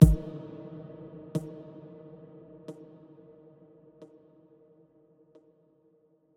Big Drum Hit 05.wav